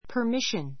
pə r míʃən パ ミ ション